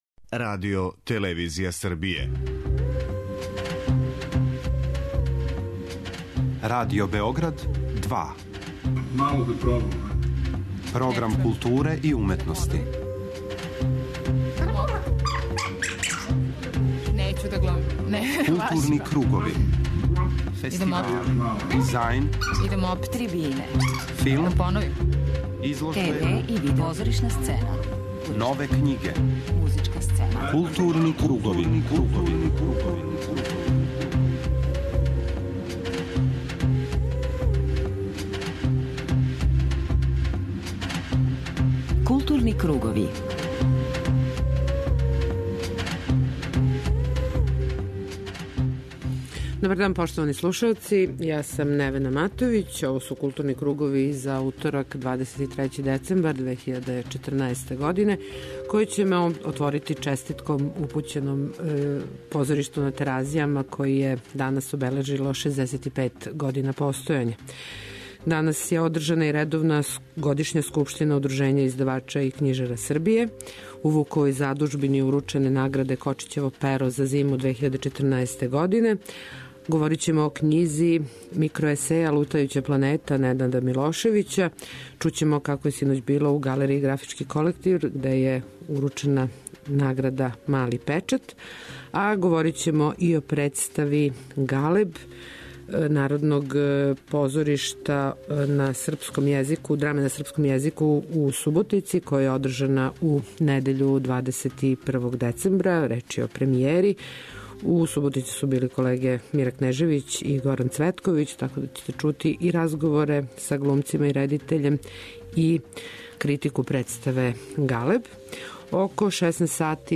Целодневним маратоном и низом интересантних програма и ове године је у нашој земљи обележен овај дан, а у ДОБ-у је одржана и трибина Јутопија или како су ме издали сопствени идеали , са које ћете чути најзанимљивије звучне белешке.